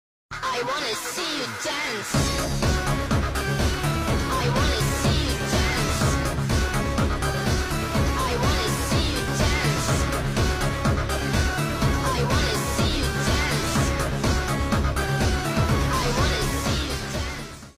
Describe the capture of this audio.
the quality got ruined 💔